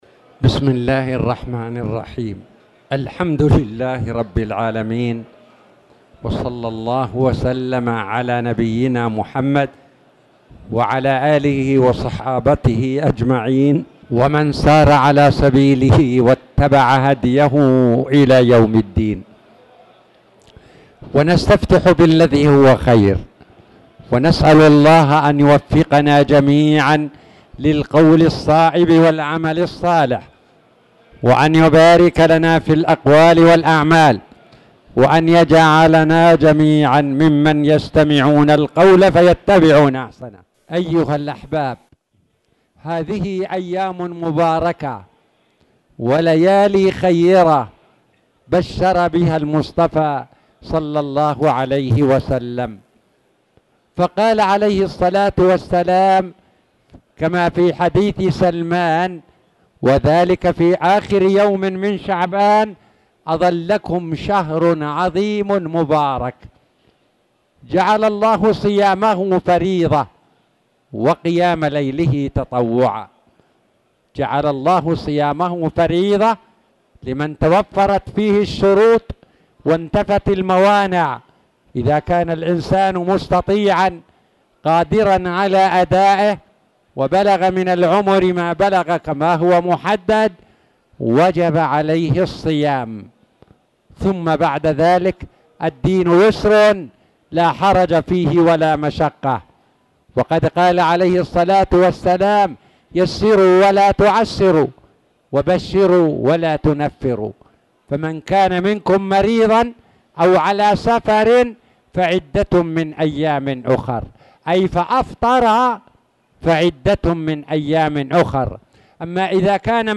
تاريخ النشر ٣ رمضان ١٤٣٧ هـ المكان: المسجد الحرام الشيخ